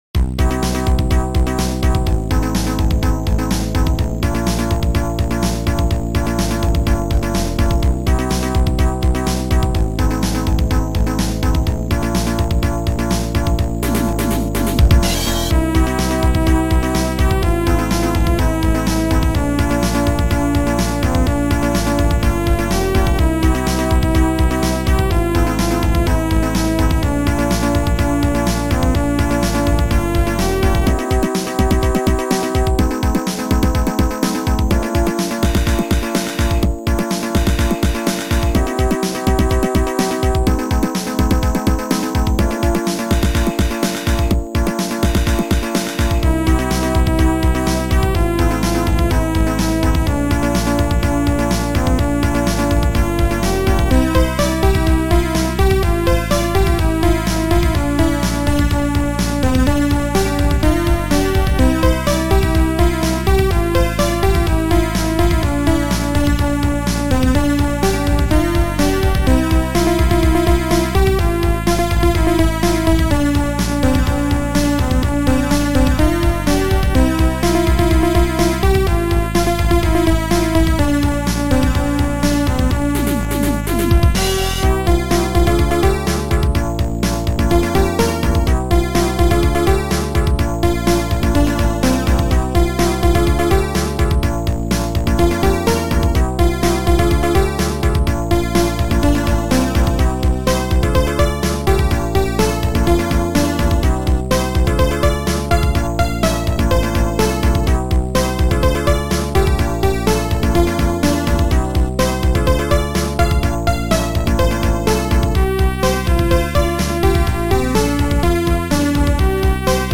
Sound Format: Noisetracker/Protracker
Sound Style: Disco